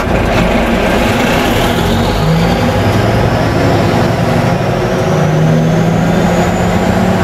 rev.wav